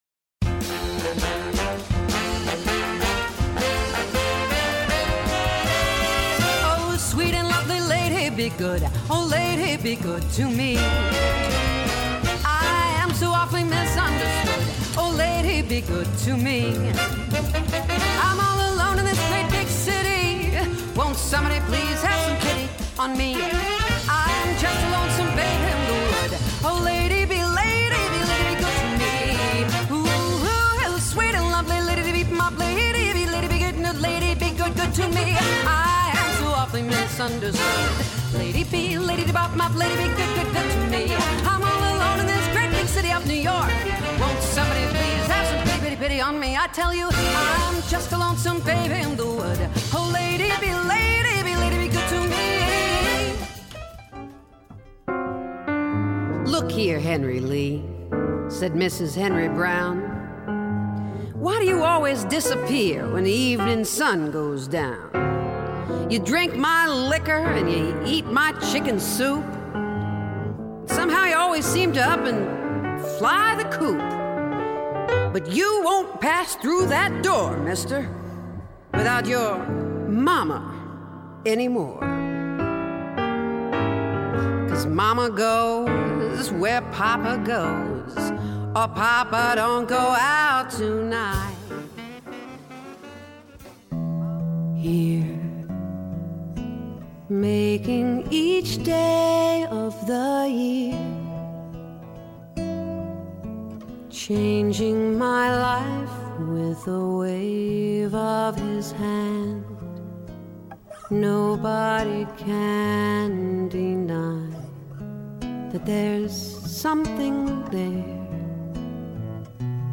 Jazz Singer
She can swing or smolder with the best.